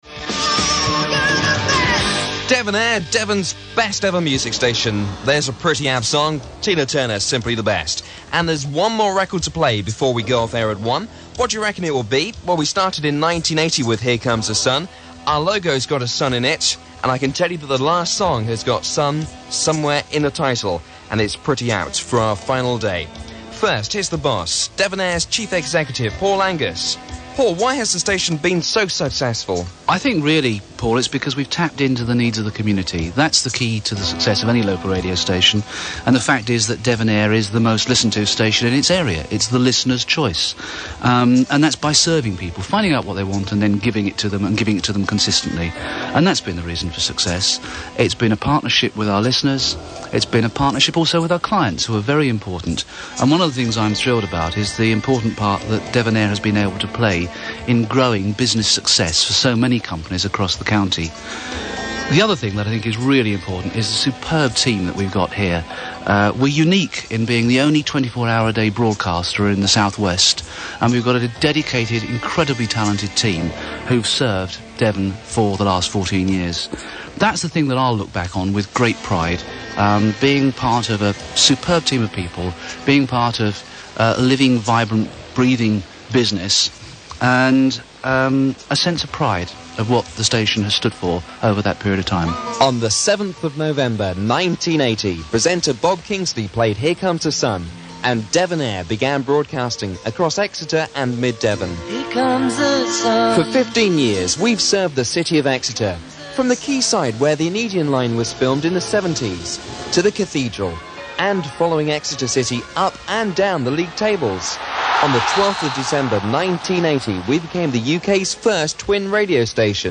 Devonair was replaced by Gemini, which became Heart. Listen here to the final moment's in Devonair's life.